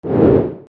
スイング(シンセ) 010
/ J｜フォーリー(布ずれ・動作) / J-20 ｜スイング(ディフォルメ)
ゴォー